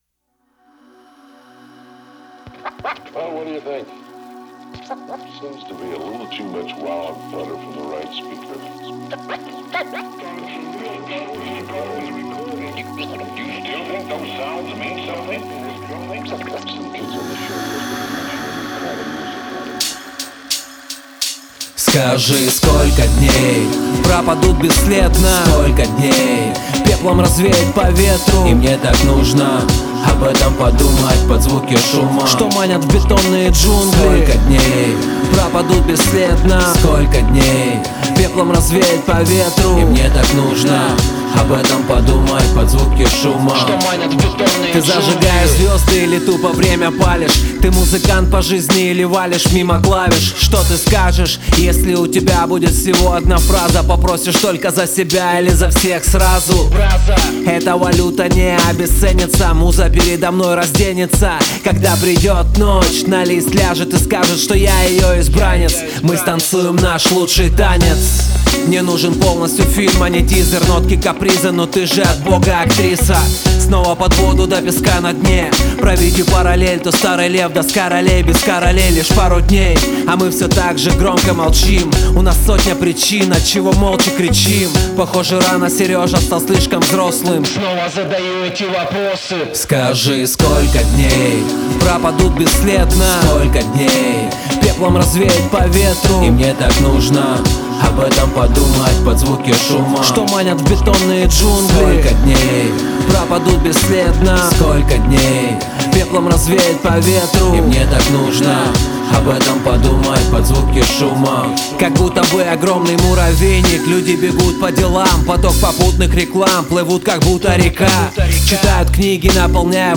Категория: Русский рэп 2016